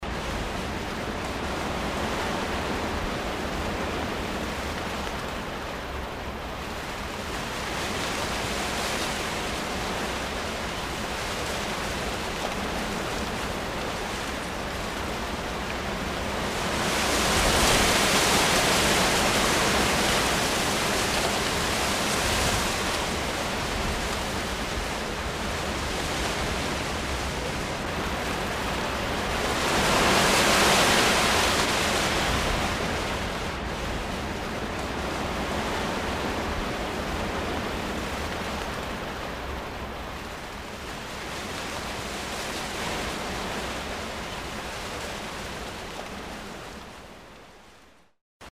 Звуки шелеста листьев
Подборка включает шум осенней листвы, летний шепот деревьев, звуки леса и парка.